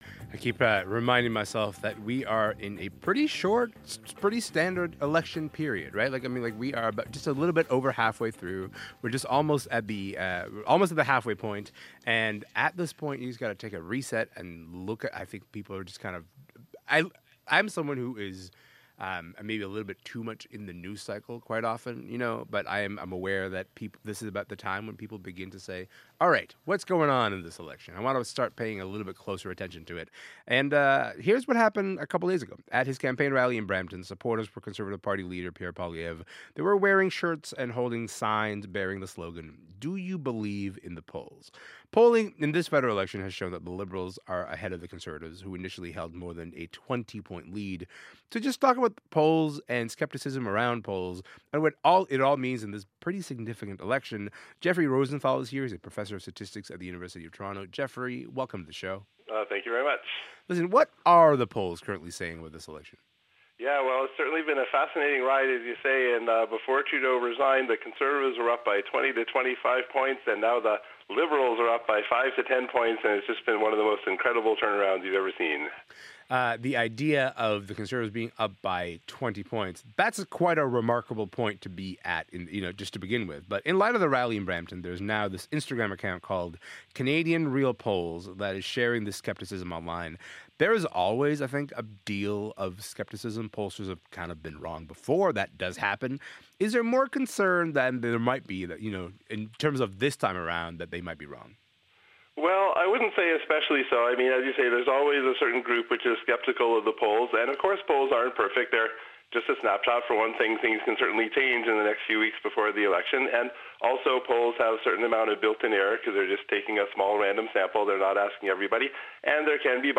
In 2025: I was interviewed on CKNW radio in Vancouver about St. Patrick's Day luck (spotify), and re federal election polls by NOW Magazine (article, mention and the Toronto Star (article) and CBC Radio Metro Morning (